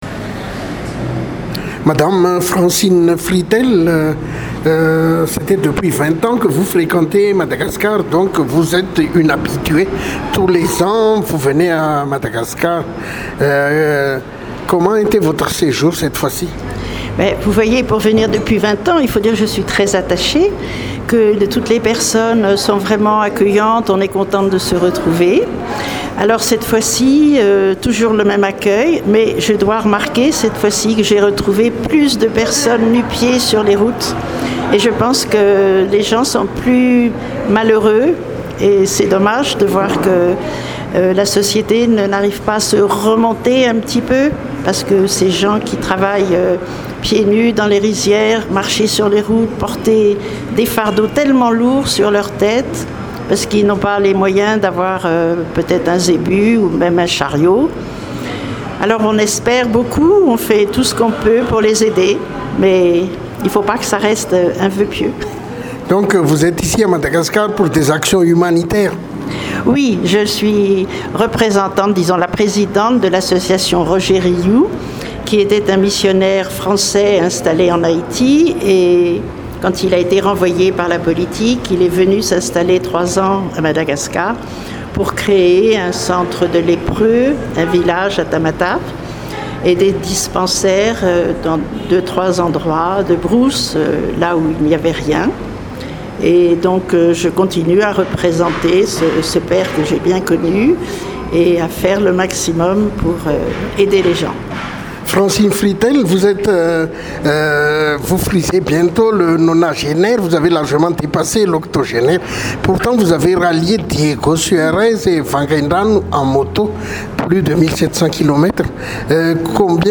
Ils ont déjà rallié Tana-Diego-Vangaindrano-Tana il y a trois ans. Interview exclusive pour Délire Madagascar.